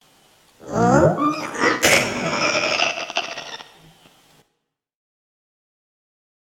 mocking demon laugh growl
creepy demon demons devil evil ghost growl haunted sound effect free sound royalty free Funny